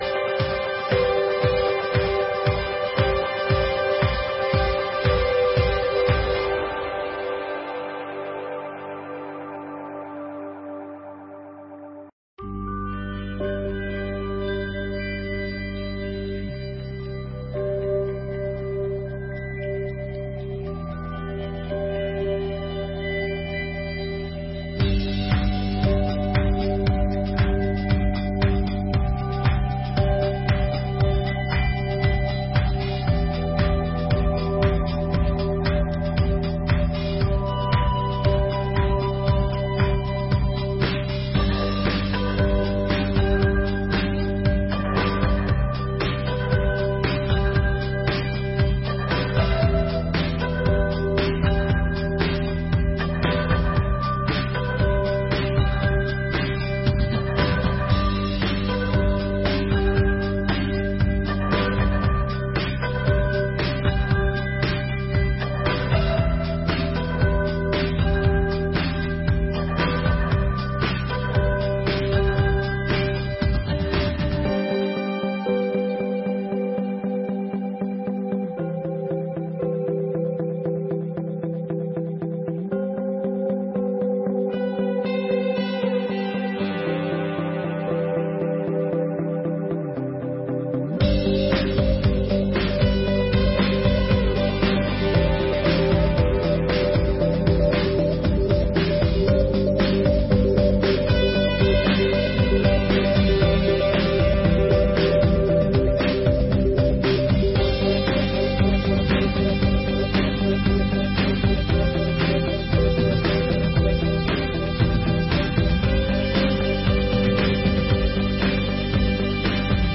Sessões Solenes de 2024